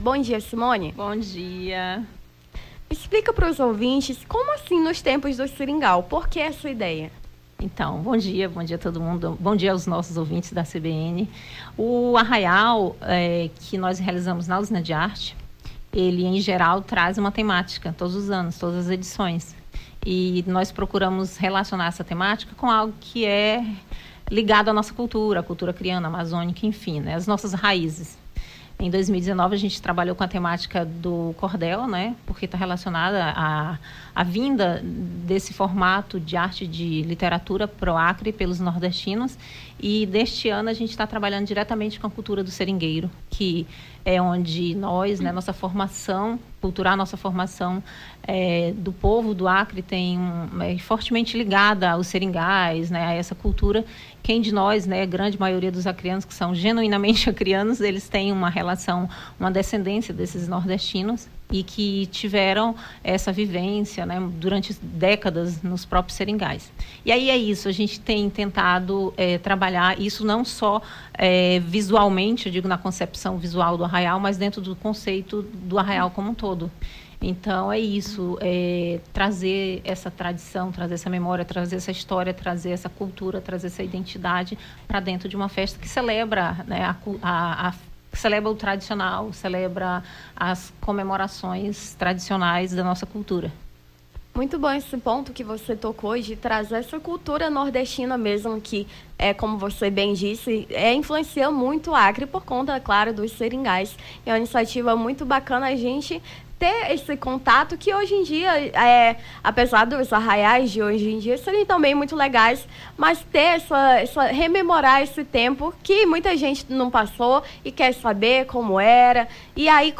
Nome do Artista - CENSURA - ENTREVISTA (ARRAIAL COMO O TEMPO DOS SERINGAIS) 21-07-23.mp3